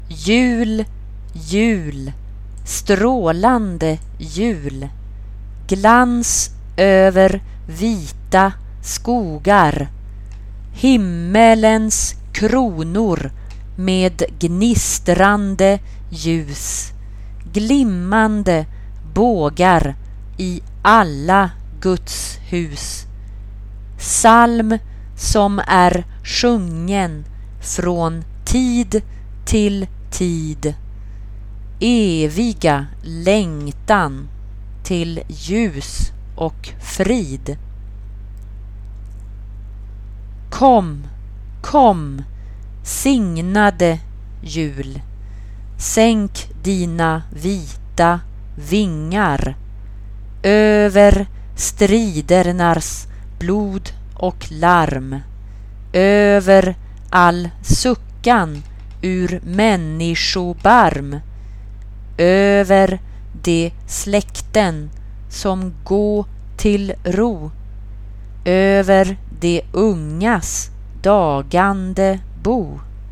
SATB (4 voices women) ; Full score.
Christmas song.